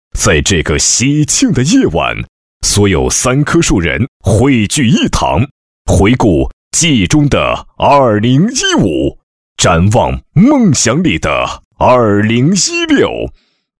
颁奖配音作品在线试听-优音配音网
男声配音 1 颁奖男国177C 2 颁奖男国183 3 颁奖男国193A 4 颁奖男国193B 5 颁奖男国196 6 颁奖男国209 7 颁奖男国215A 8 颁奖男国215B 9 颁奖男国218A 10 颁奖男国218B 11 颁奖男国225 12 颁奖男国241 13 颁奖男国245 14 颁奖男国253A 15 颁奖男国253B 女声配音 首页 上一页 1 2 3 4 下一页 尾页 3/4页